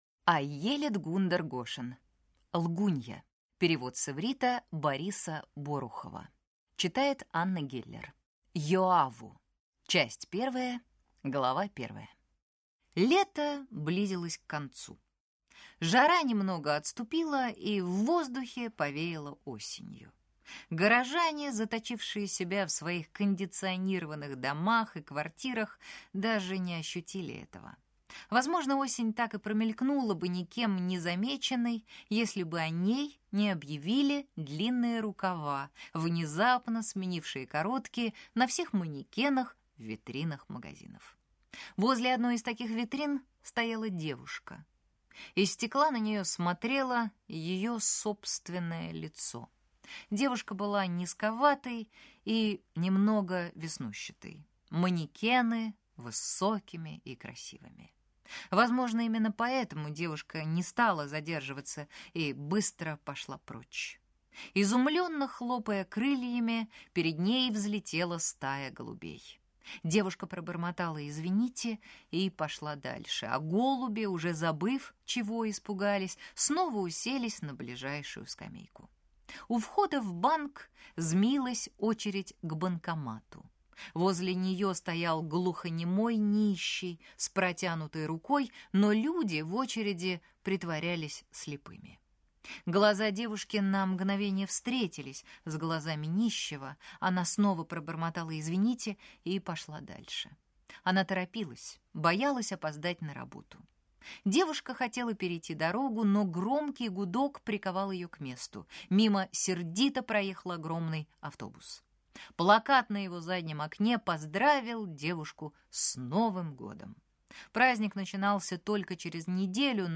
Аудиокнига Лгунья | Библиотека аудиокниг